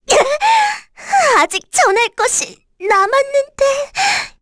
Rehartna-Vox_Dead_kr.wav